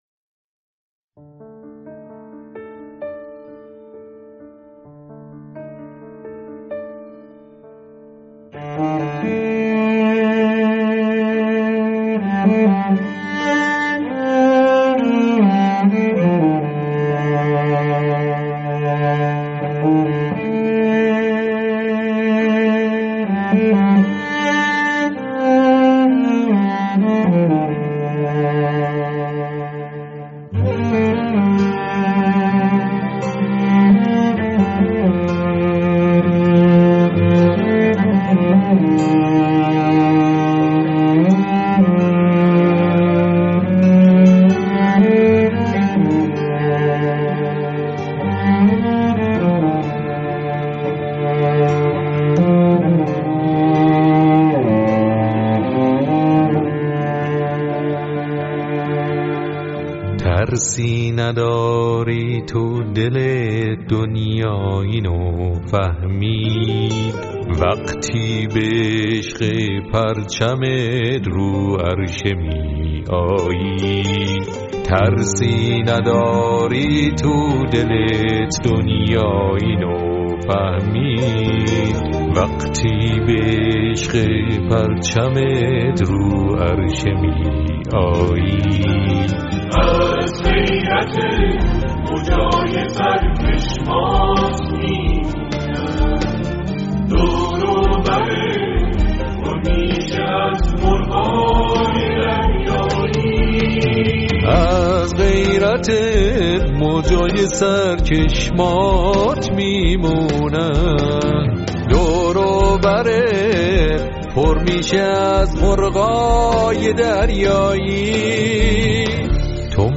شیوه اجرا: اركستر